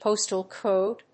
アクセントpóstal còde